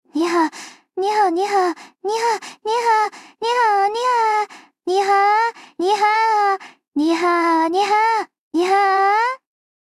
GPT-SoVITS - 1 min voice data can also be used to train a good TTS model! (few shot voice cloning)